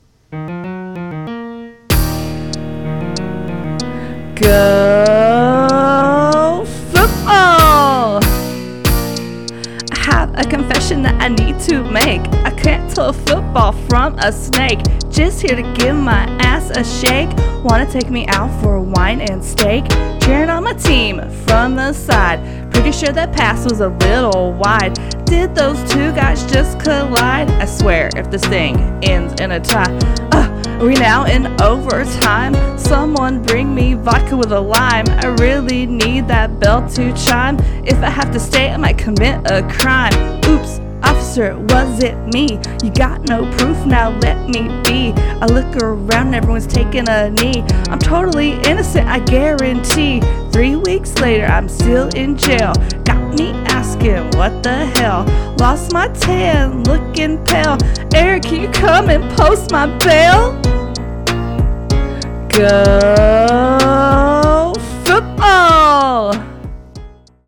NFL-Blitz-rap.mp3